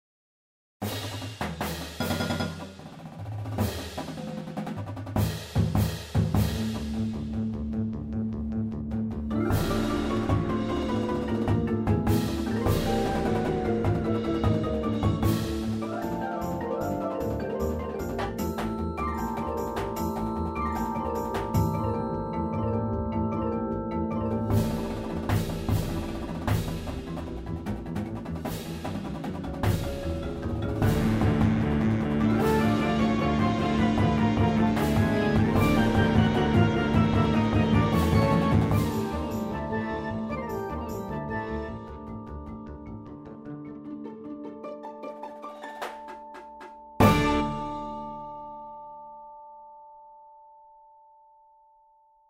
Percussion Feature